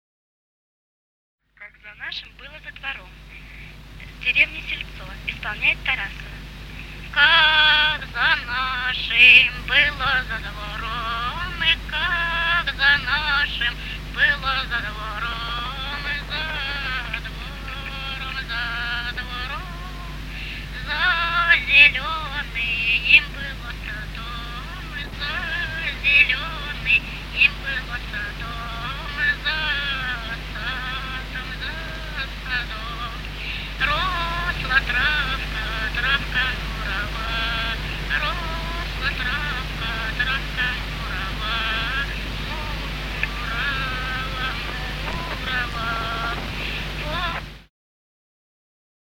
Русские народные песни Владимирской области [[Описание файла::25. Как за нашим было за двором (хороводная) с. Сельцо Суздальского района Владимирской области.